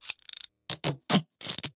repair6.ogg